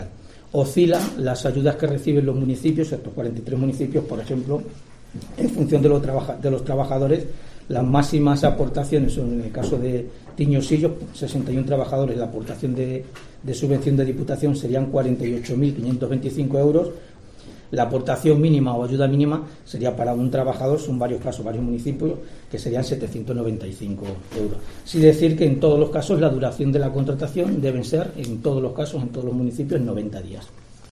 Portavoz del Equipo de Gobierno de Diputación de Ávila, Juan Carlos Suárez Mesón